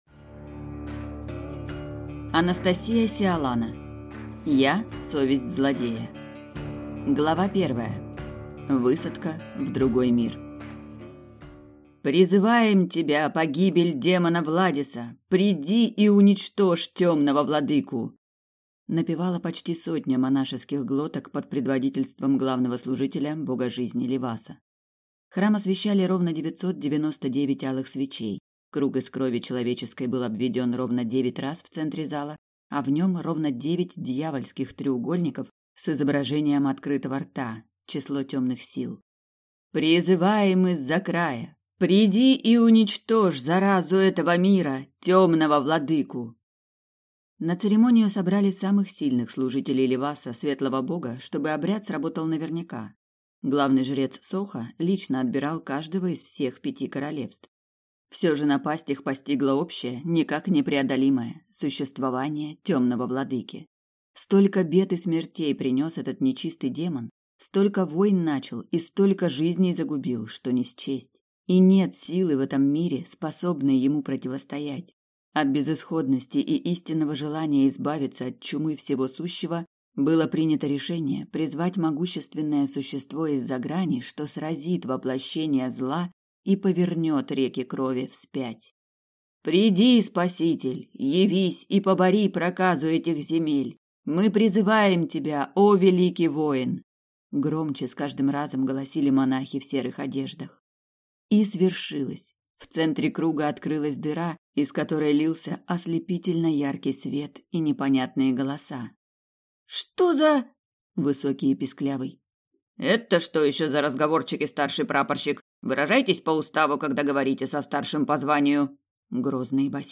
Я – Совесть злодея (слушать аудиокнигу бесплатно) - автор Анастасия Сиалана